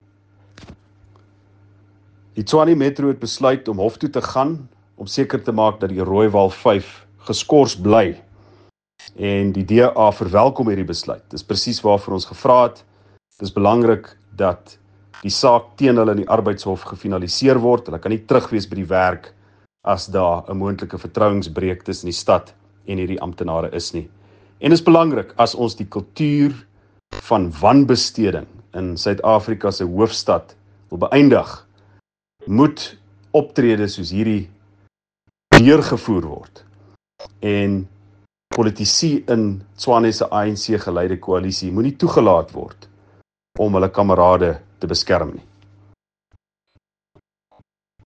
Note to Editors: Please find English and Afrikaans soundbites by Ald Cilliers Brink